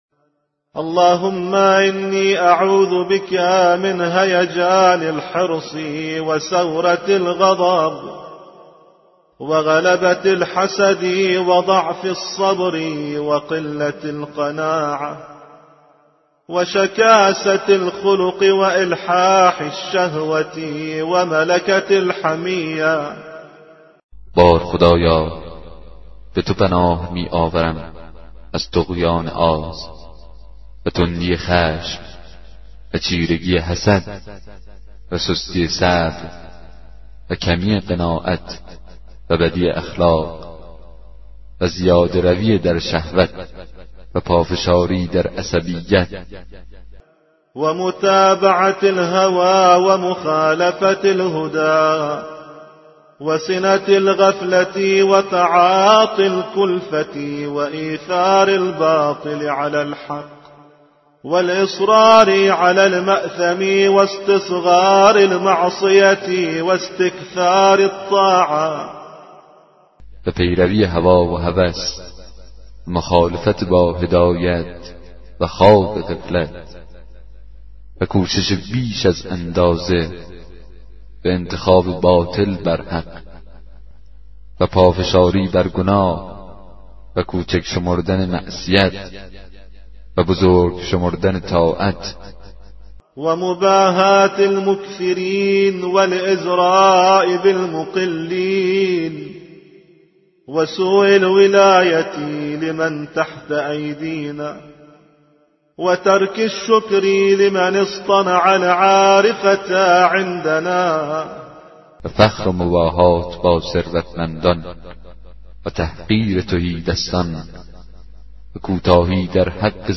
کتاب صوتی دعای 8 صحیفه سجادیه